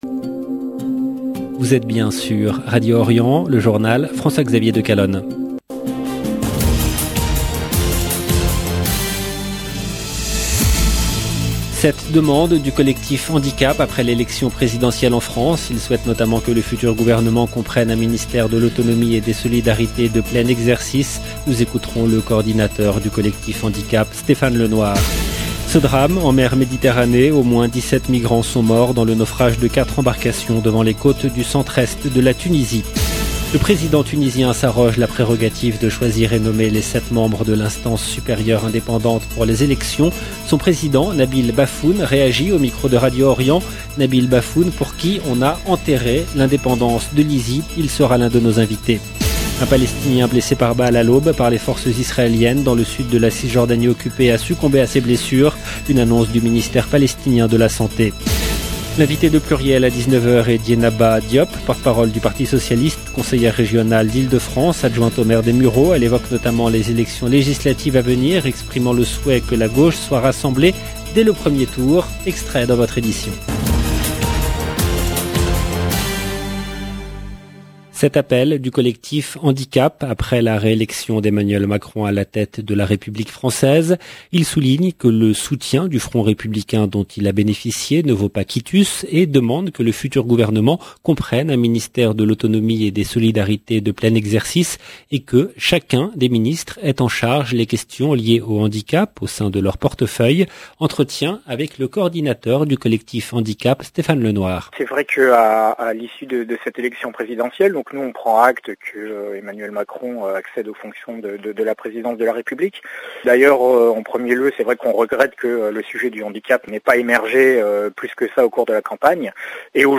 Le journal du soir en langue française du soir du 26/04/22
Son président Nabil Baffoun réagit au micro de Radio Orient.